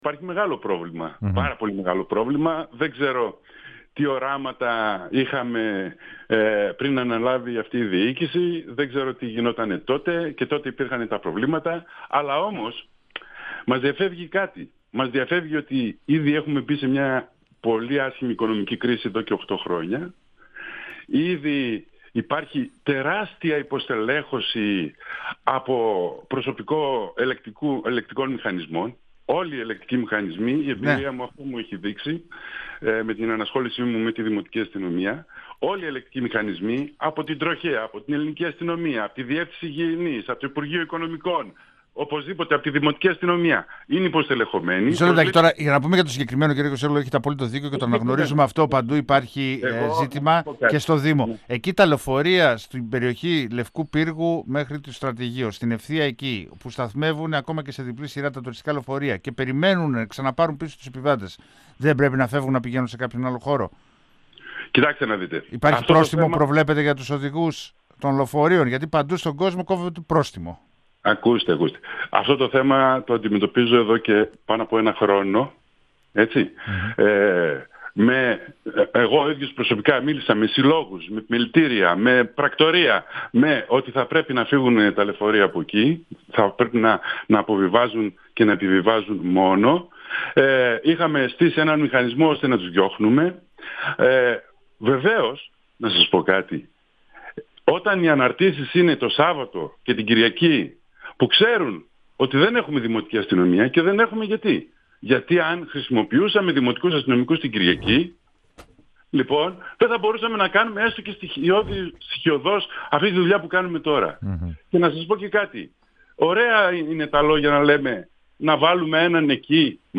Ο εντεταλμένος δημοτικός σύμβουλος για θέματα Δημοτικής Αστυνομίας, Λευτέρης Κιοσέογλου, στον 102FM του Ρ.Σ.Μ. της ΕΡΤ3
Συνέντευξη